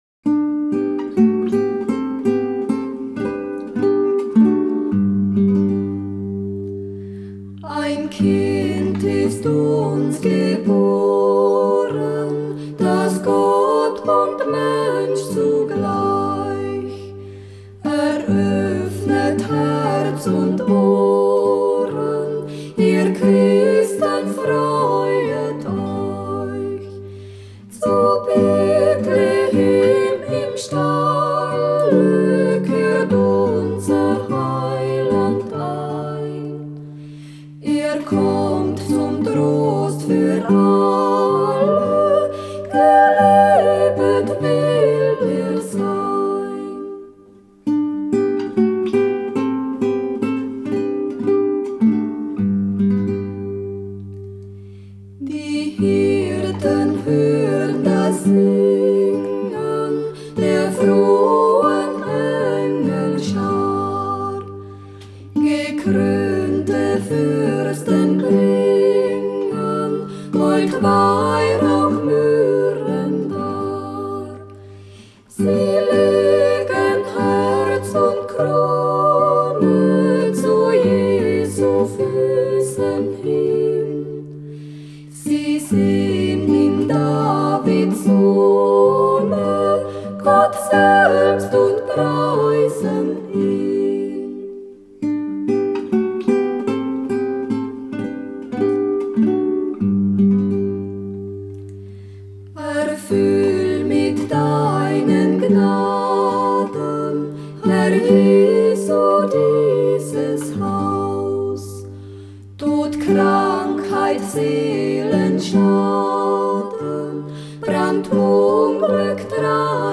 Villancico navideño bávaro
Existen dos melodías para este canto, ambas en un balanceado compás ternario y algo similares entre sí. Ein Kind ist uns geboren es interpretado a continuación por Radlmoser Dreigesang.